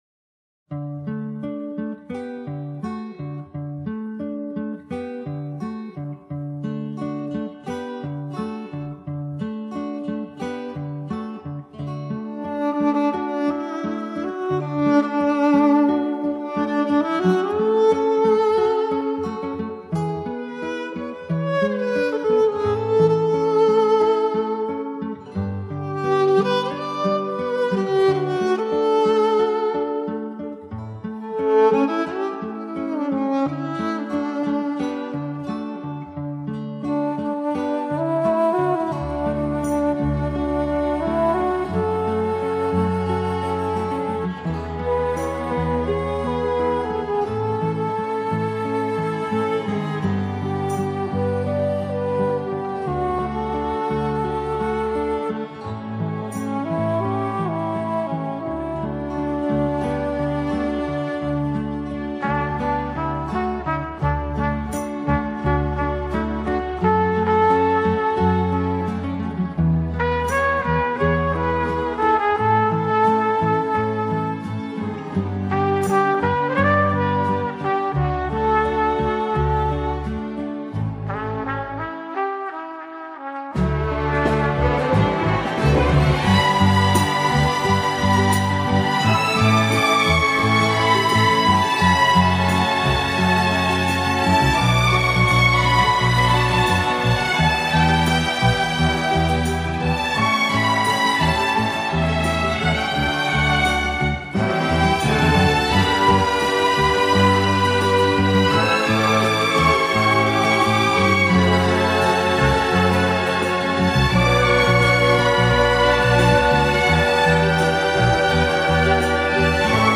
Genre:Folk,World,& Country